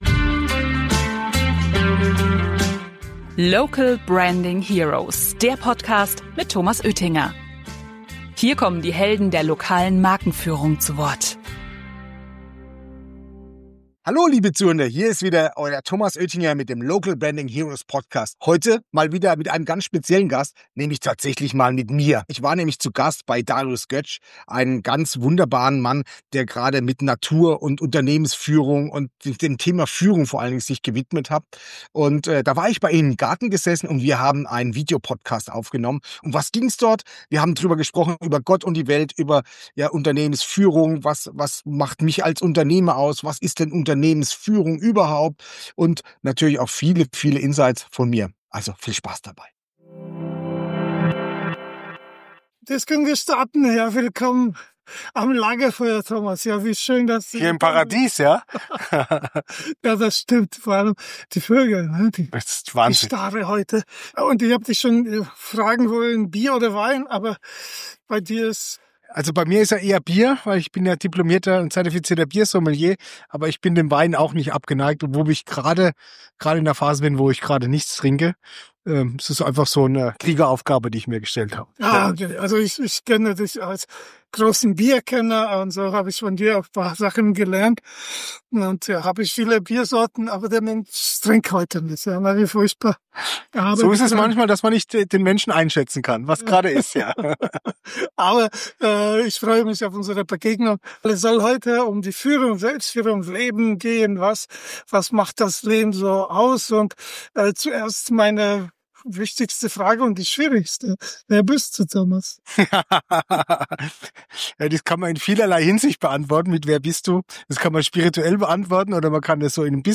Das Gespräch findet an einem besonderen Ort statt: am Lagerfeuer, mitten in der Natur.